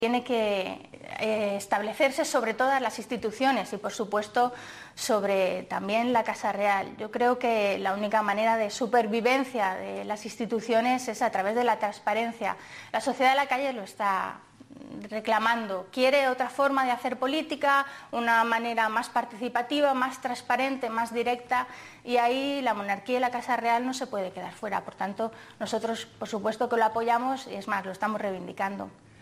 Carmen Montón en los Desayunos de TVE opina sobre la inclusión de la Casa Real en la Ley de Transparencia 8/04/2013